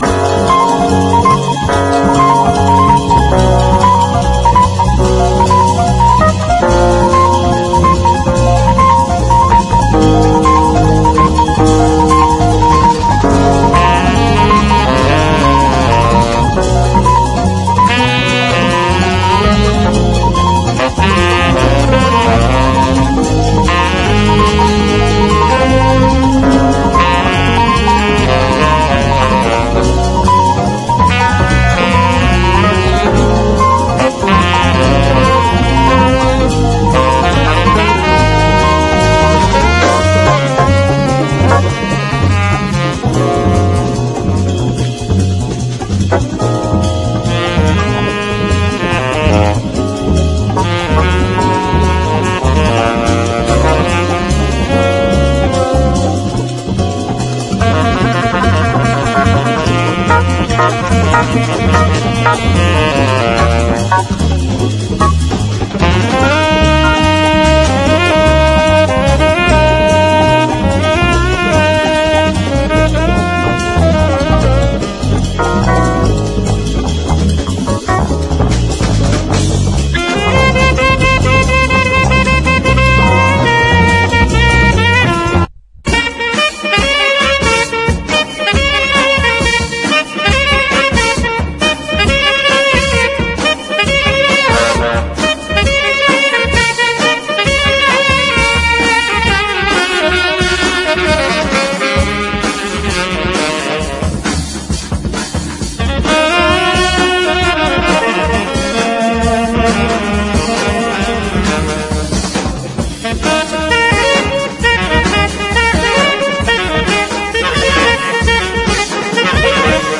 全編でコーラスをフィーチャーしたスピリチュアル・ジャズ・クラシック！